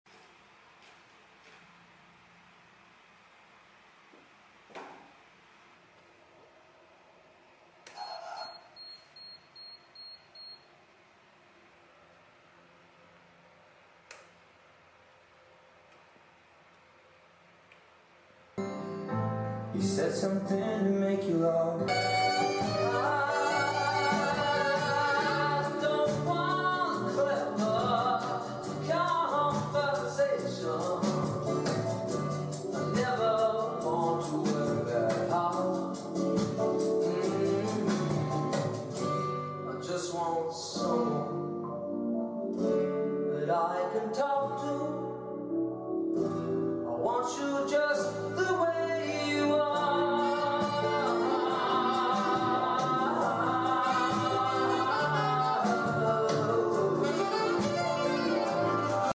Testing salah satu brand speaker sound effects free download
Acoustic Research AR 18b | ini speaker spesial menurut saya. 2 way system, kecil tp suaranya lumayan bagus buat saya pribadi.